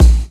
• 00's Short Hip-Hop Kick Drum A# Key 327.wav
Royality free kick drum single hit tuned to the A# note. Loudest frequency: 317Hz
00s-short-hip-hop-kick-drum-a-sharp-key-327-GKm.wav